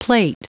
Prononciation du mot plait en anglais (fichier audio)
Prononciation du mot : plait